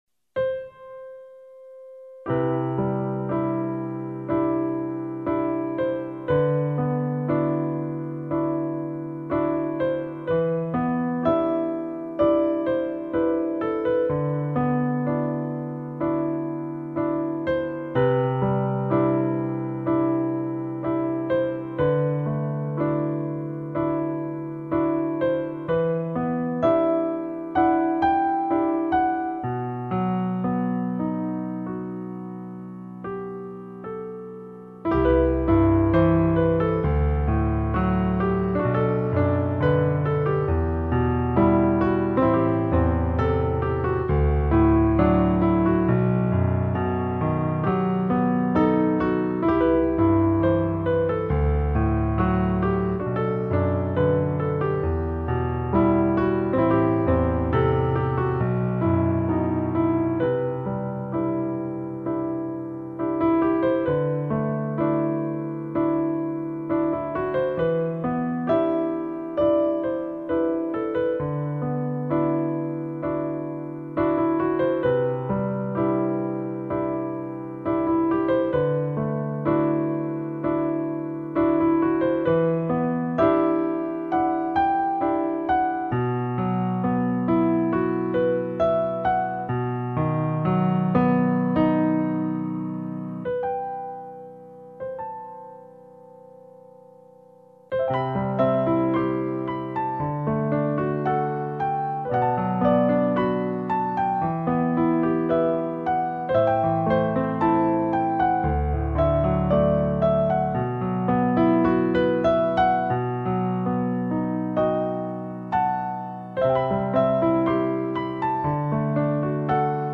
天籁钢琴, 经典配乐 你是第7197个围观者 0条评论 供稿者： 标签：, ,